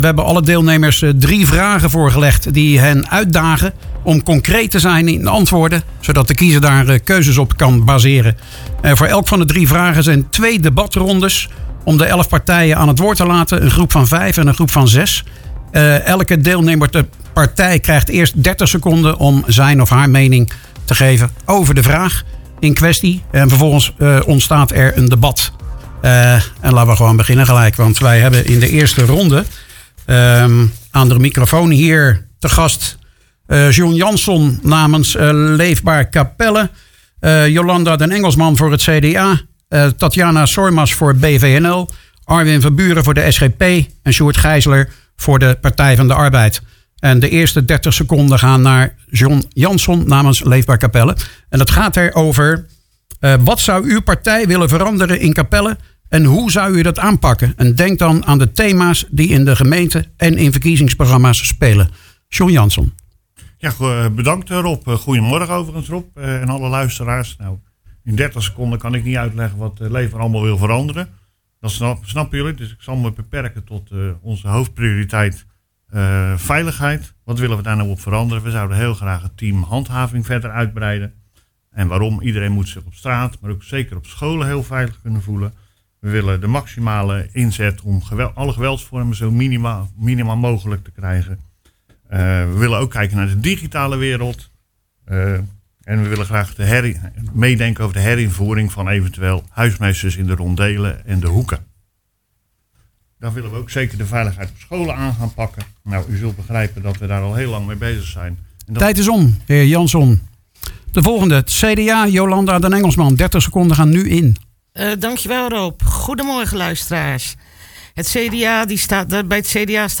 Op zaterdag 12 maart was in de studio van Radio Capelle het laatste verkiezingsdebat voordat de stembureaus opengaan.